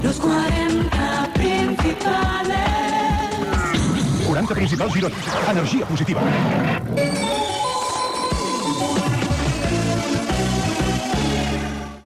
Identificació de l'emissora i indicatiu
FM